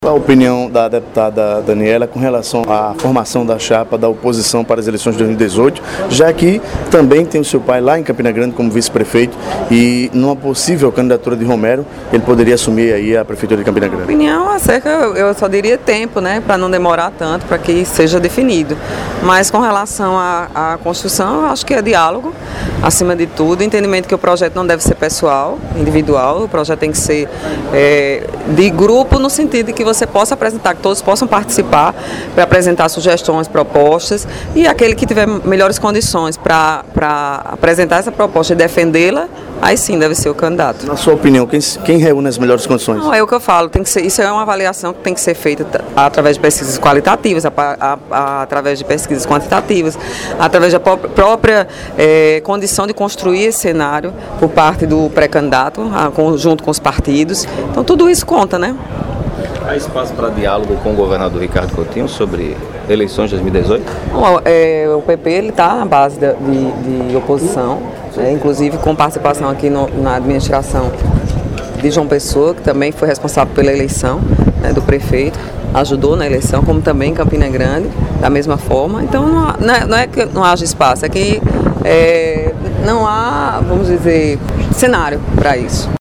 A deputada Daniella Ribeiro (PP) avaliou durante entrevista ao PBVale, a expectativa para o início dos trabalhos legislativos neste ano 2018. Ainda segundo a parlamentar, em relação a definição do nome das oposições, Ribeiro defendeu que o nome seja definido o mais rápido possível.